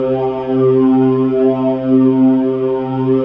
PAD JUSTI00L.wav